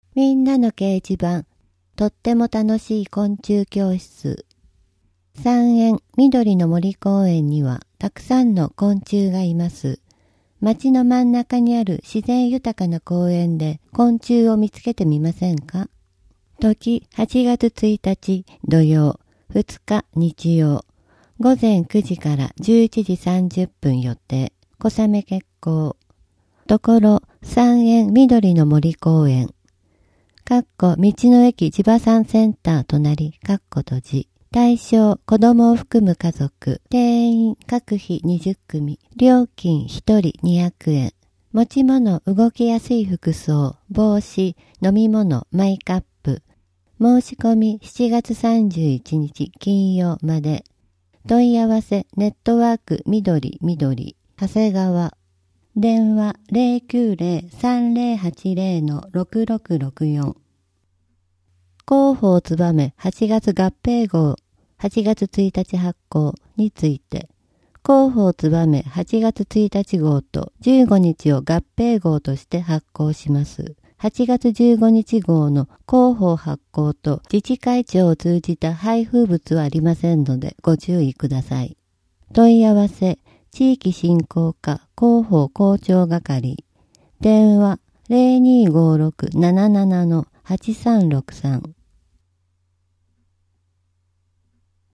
こちらではMP3版の声の広報を、項目ごとに分けて配信しています。